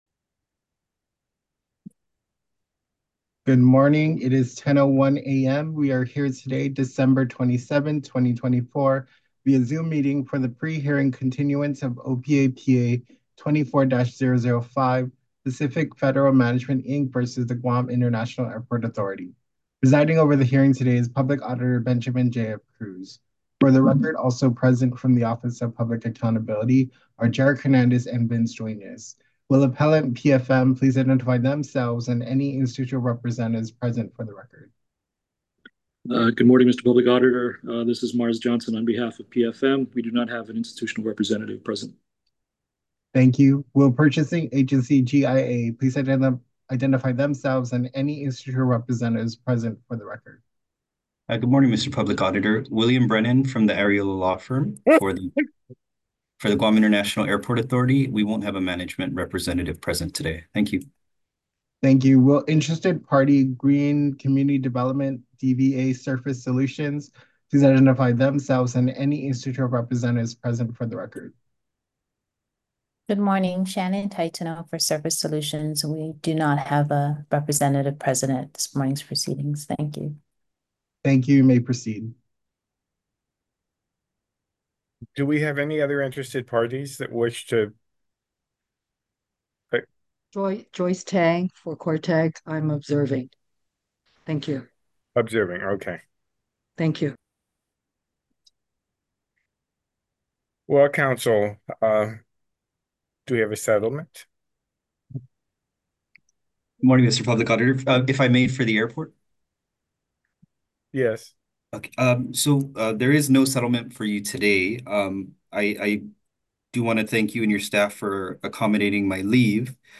Pre-Hearing Conference Continuance - December 27, 2024